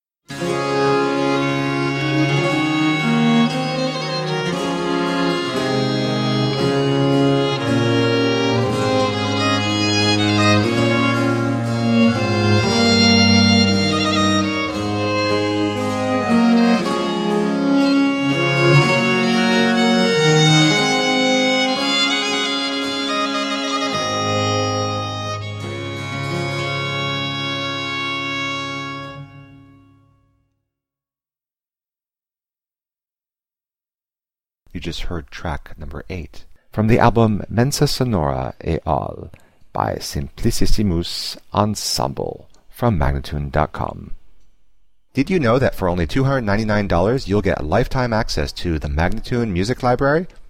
17th and 18th century classical music on period instruments.